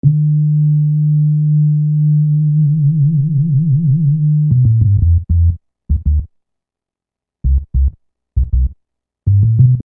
Bass 16.wav